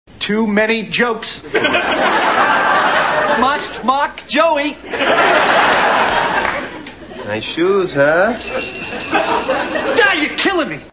These are .mp3 soundbites from the NBC television show "Friends."